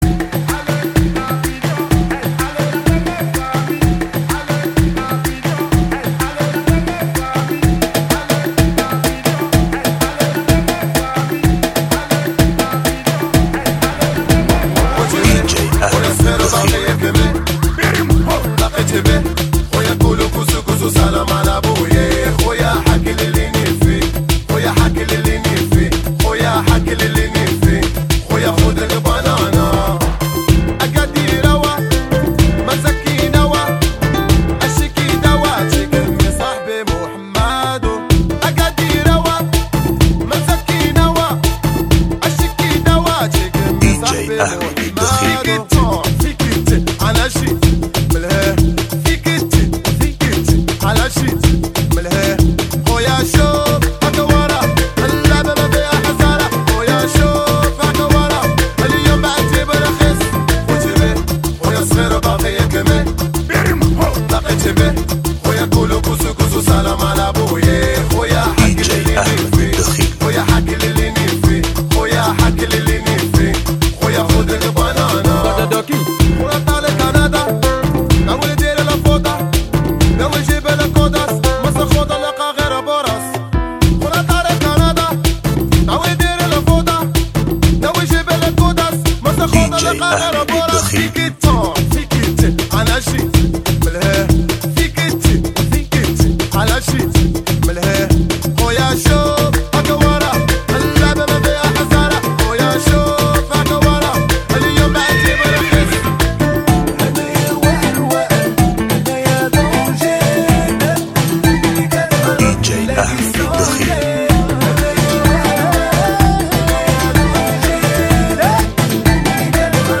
Funky Remix